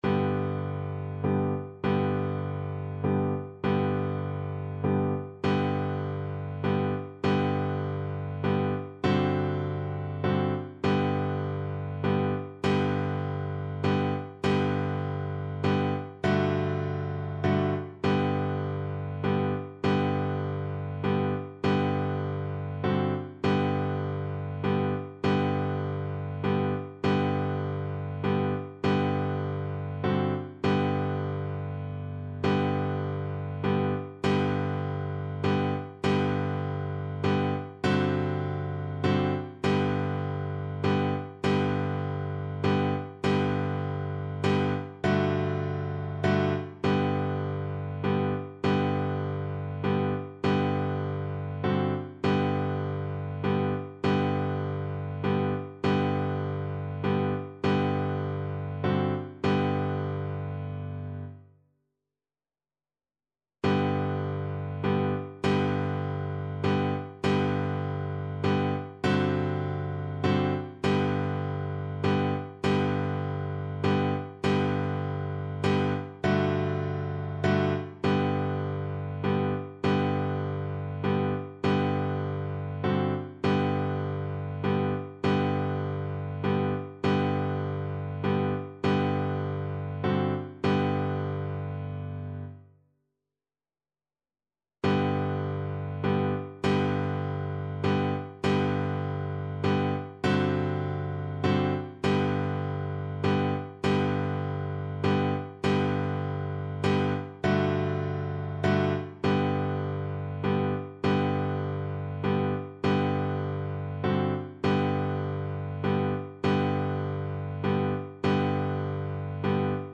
Symulacja akompaniamentu